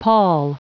Prononciation du mot pall en anglais (fichier audio)
Prononciation du mot : pall